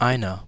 Ääntäminen
RP : IPA : /ˈwʌn/ US : IPA : [wʌn]